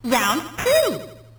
snd_boxing_round2_bc.wav